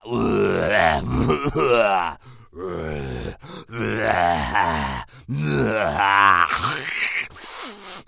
描述：Short processed sample of mouthsounds, in Dutch 'smeks'
标签： close effect fx human monster monsters mouth processed vocal
声道立体声